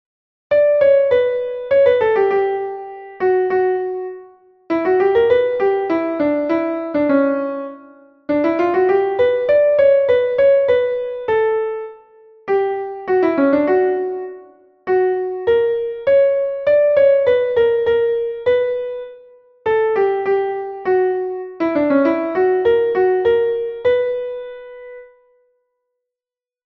Entoación a capella
Imos practicar a entoación a capella coas seguintes melodías.
entonacion7.5capela.mp3